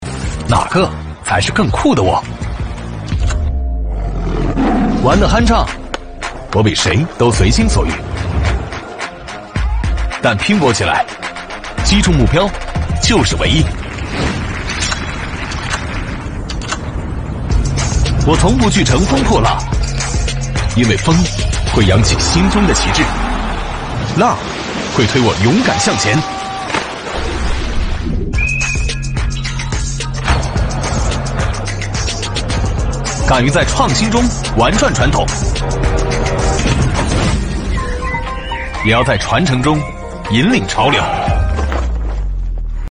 男3号（品质、洪亮）
男3-年轻前卫-深圳融创.mp3.mp3